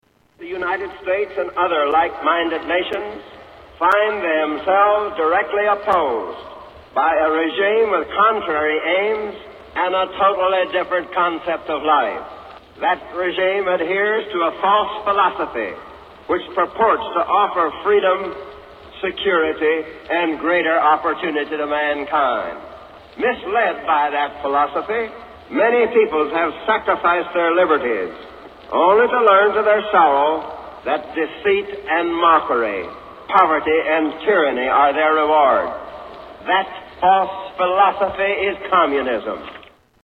Tags: History Presidents Of the U. S. President Harry S. Truman Speeches